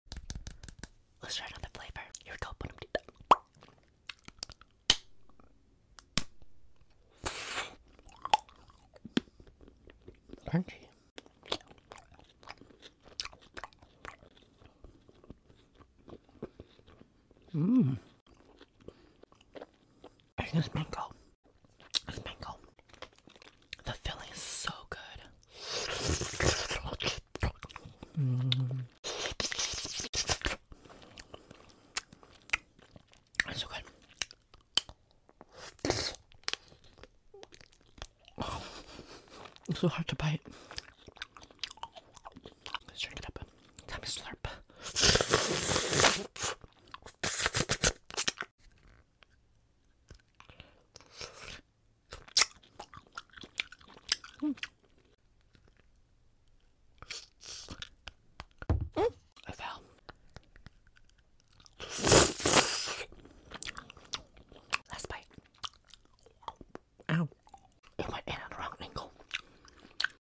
Eating FROZEN wax candy asmr! sound effects free download